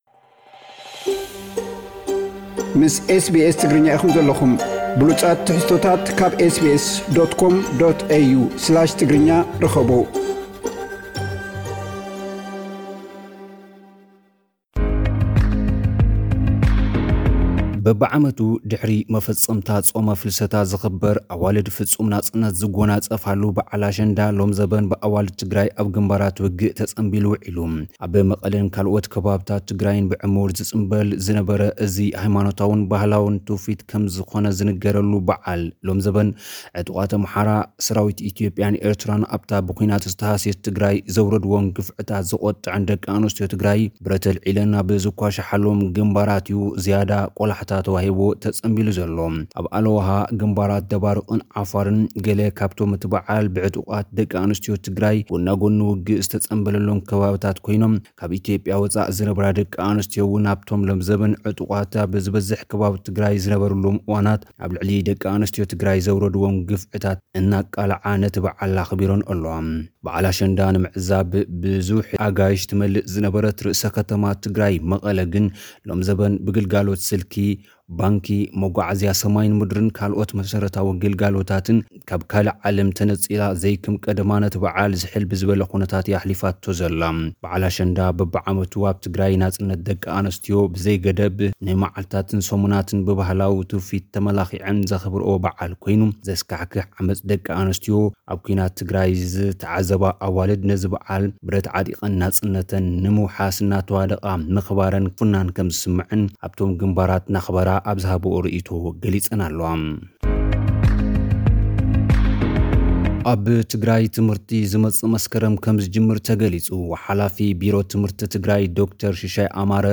ኣርእስታት ዜና: በዓል ኣሸንዳ 2013 ዓ.ም ብኣዋልድ ትግራይ ኣብ ግንባራት ውግእ ይጽምበል። ሱዳንን ደቡብ ሱዳንን ልዕሊ 11 ዓመታት ዝዓጸወኦ ዶብ ንግዳዊ ንጥፈታት ከሰላስላሉ ይስማምዓ። ሓላፊት ዓለምለኻዊ ረድኤት ኣሜሪካ ሳማንታ ፓወር ኣብተን ሓይልታት ትግራይ መጥቃዕቲ ዝኸፈቱለን ክልላት ንዝተመዛበሉ ልዕሊ 136 ሽሕ ሰባት ትካለን ሰብኣዊ ረድኤት ከም ዘቕረበ ብምግላጽ ዕጡቓት ህወሓት ካብተን ክልላት ወጺኦም ክላዘቡ ሓቲተን። ኣብ ትግራይ ምዝገባ ትምህርቲ ይጅመር ኣሎ ተባሂሉ።